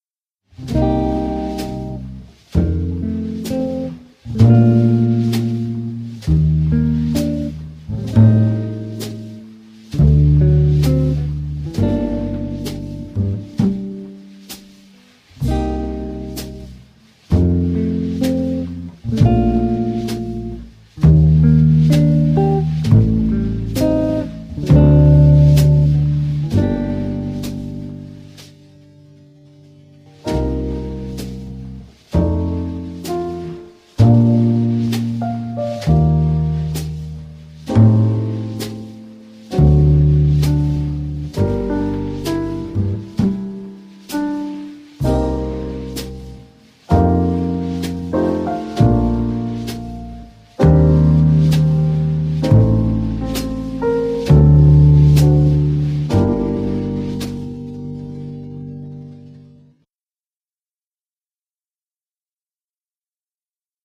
Backing Track http